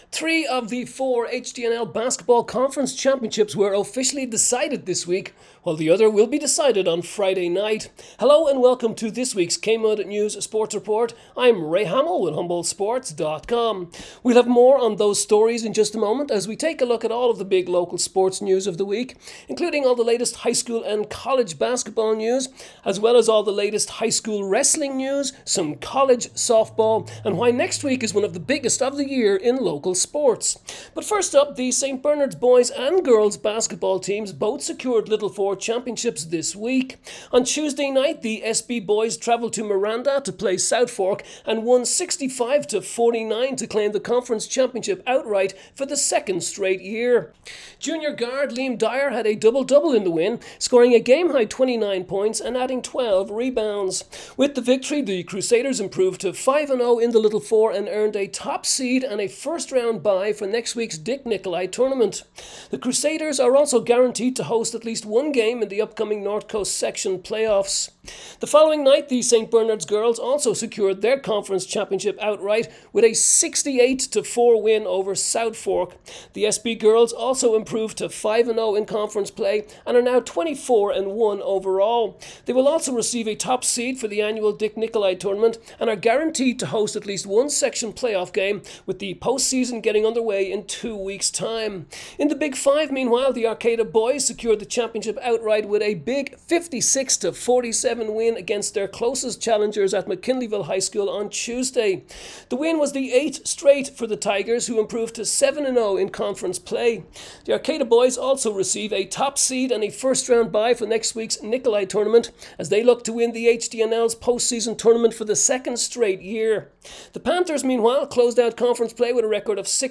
KMUD News sports report Feb 7